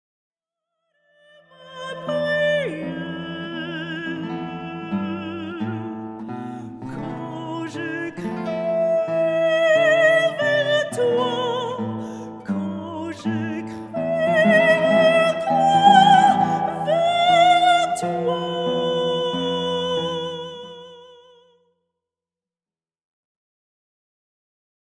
Chants sacrés
La rencontre de la guitare et de la voix.
accompagnées à la guitare